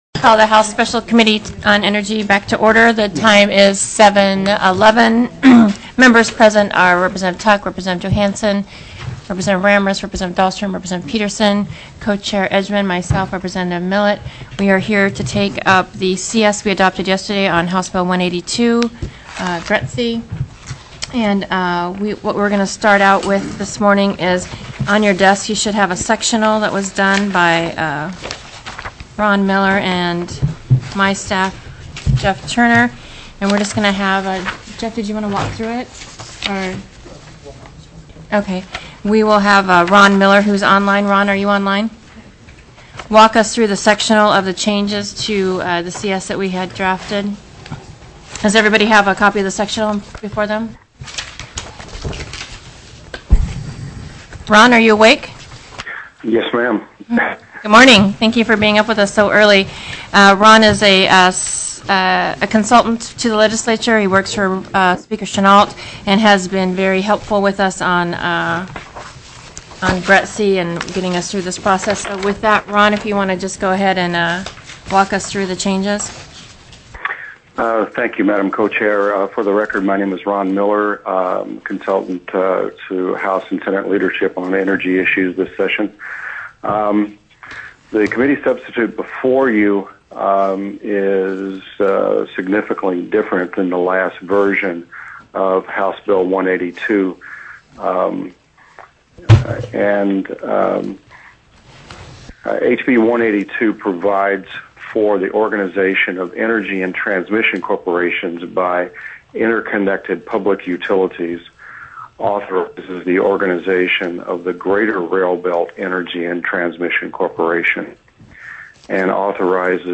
04/07/2010 07:00 AM House ENERGY
HB 182 RAILBELT ENERGY & TRANSMISSION CORP. TELECONFERENCED